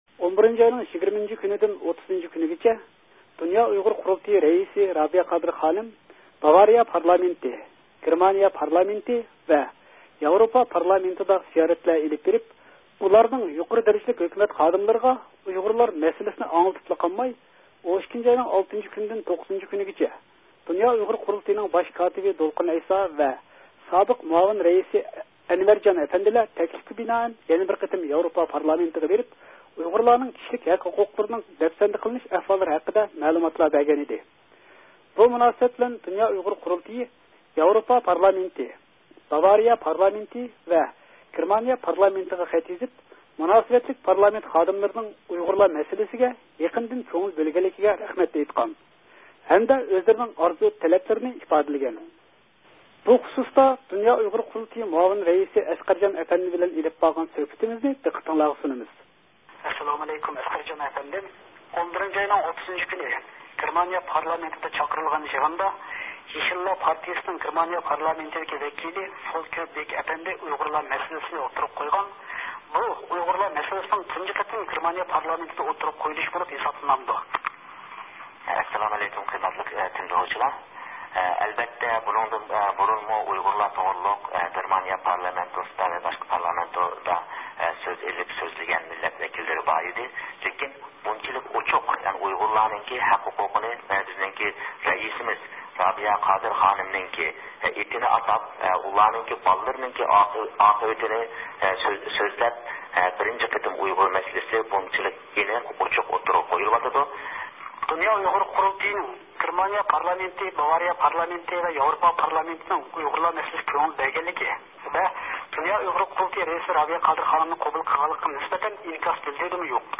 ئېلىپ بارغان سۆھبىتىنى دىققىتىڭلارغا سۇنىمىز.